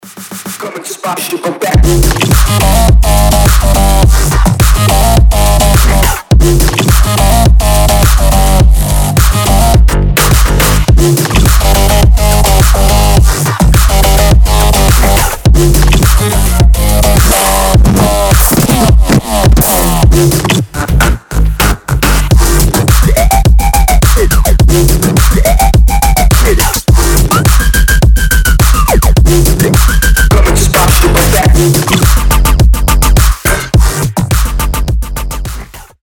• Качество: 320, Stereo
громкие
жесткие
мощные
EDM
Bass House
качающие
взрывные
страшные
Супер жёсткая музыка на звонок для самых смелых